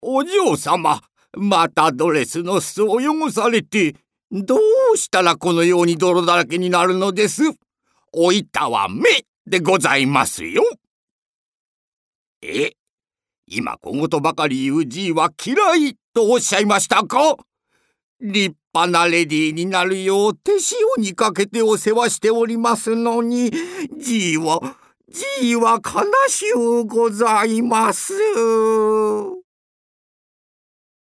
アトリエピーチのサンプルボイス一覧および紹介